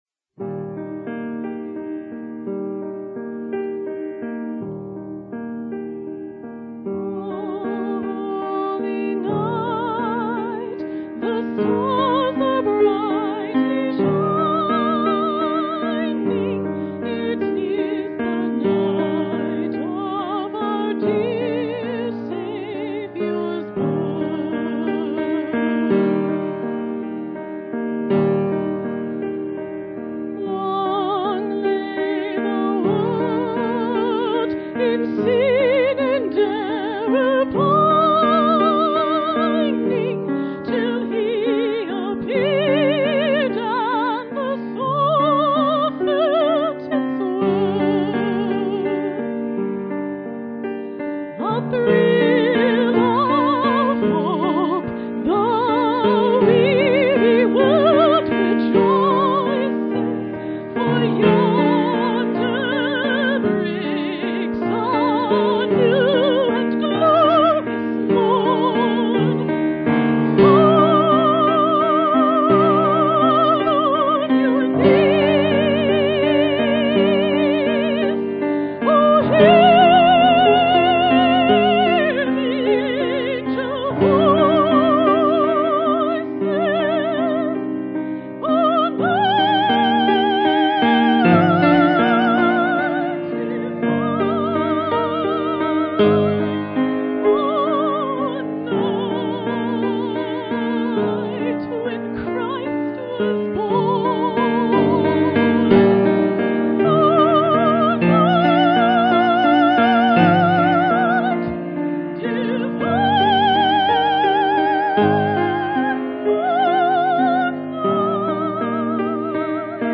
Sunday Morning Music - December 23, 2012
Solo